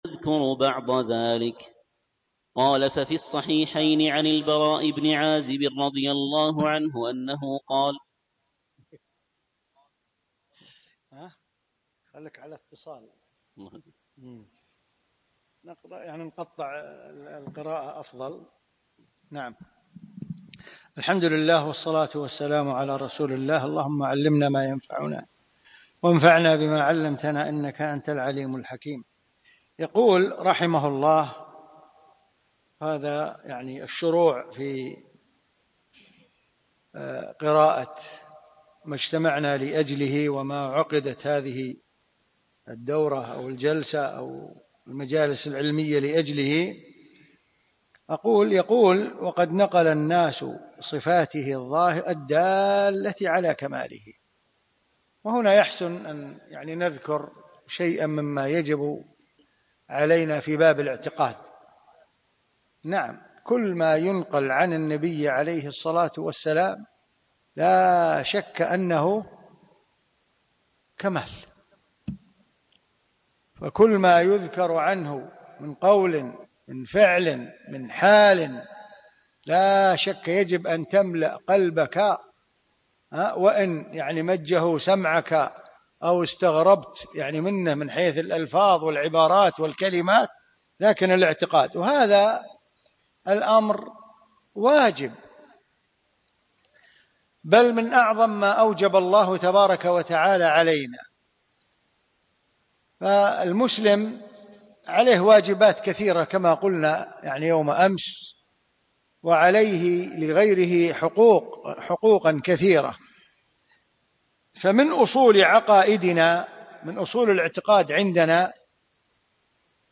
يوم الثلاثاء 6 شعبان 1438 الموافق 2 5 2017 في مسجد عائشة المحري المسائل